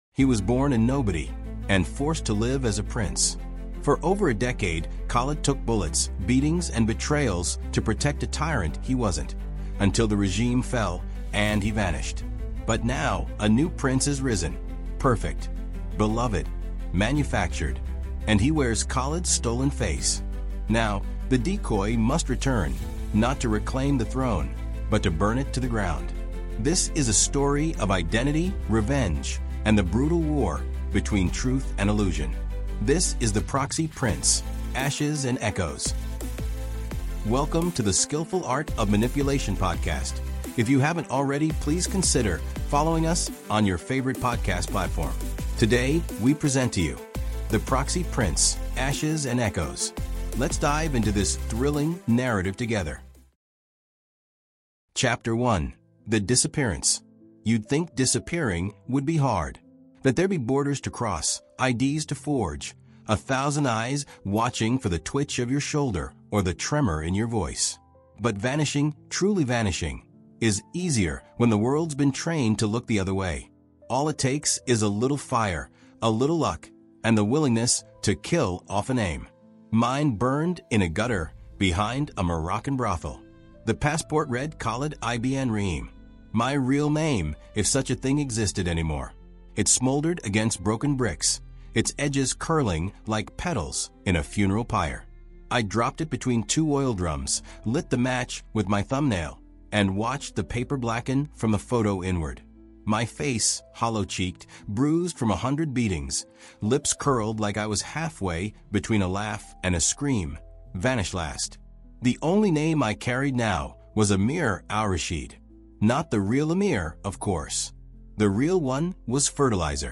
The Proxy Prince: Ashes and Echoes | Audiobook